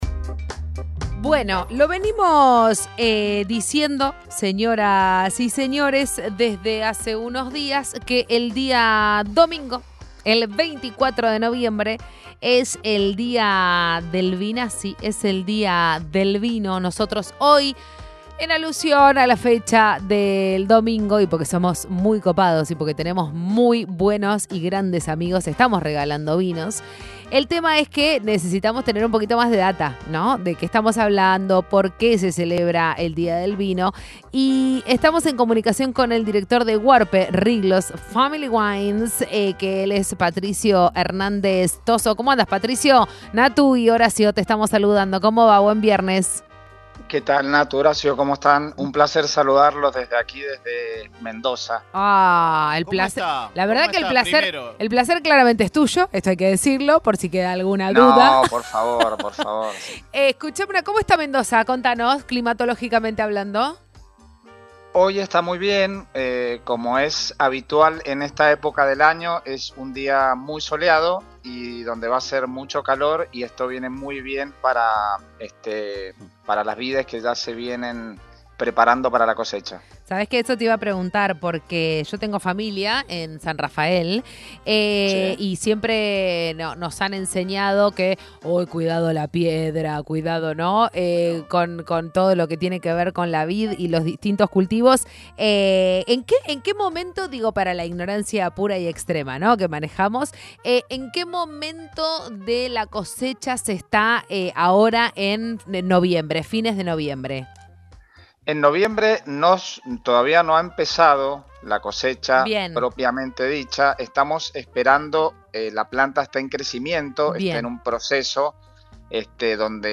entrevista-dia-del-vino-argentino.mp3